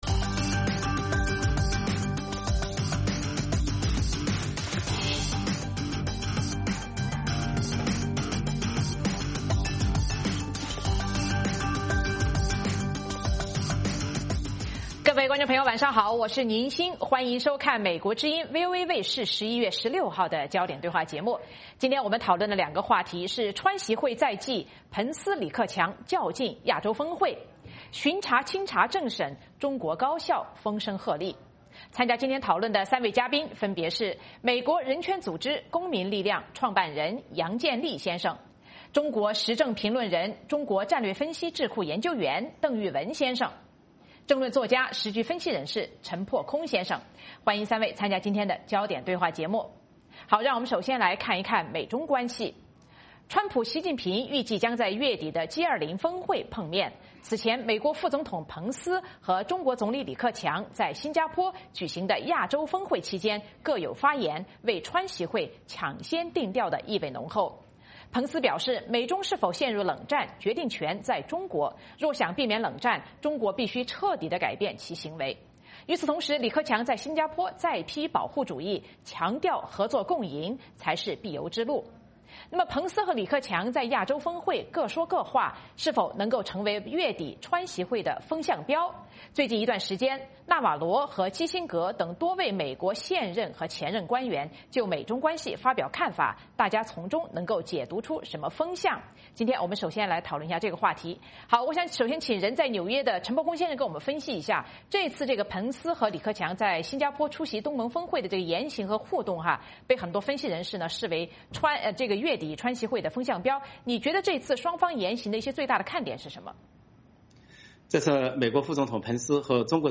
《焦点对话》节目追踪国际大事、聚焦时事热点。邀请多位嘉宾对新闻事件进行分析、解读和评论。